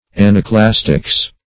anaclastics - definition of anaclastics - synonyms, pronunciation, spelling from Free Dictionary